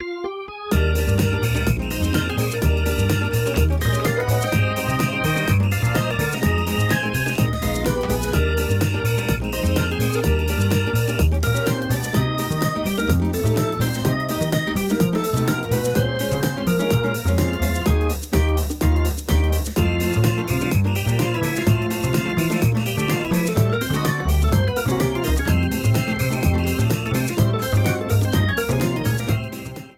Whistle Frontrunning music